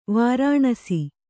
♪ vārāṇasi